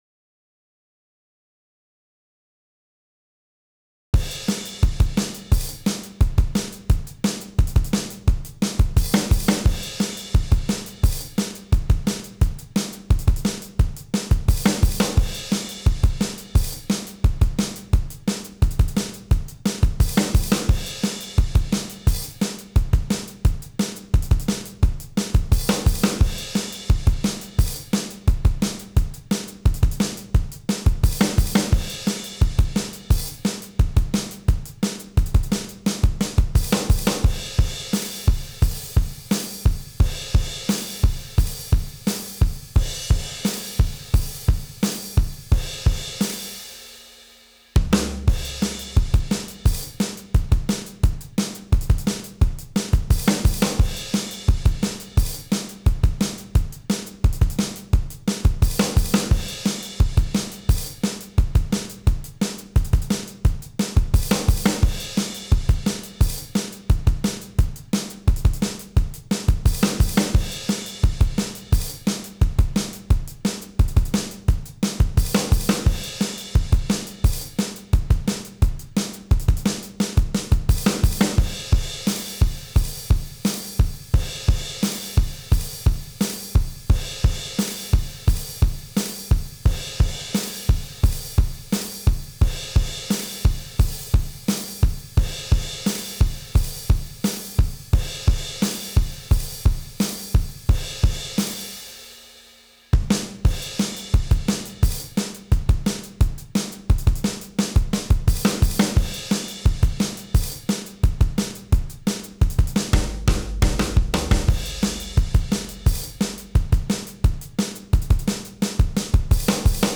Face First Drums 174bpm
face-first-drums-174bpm.wav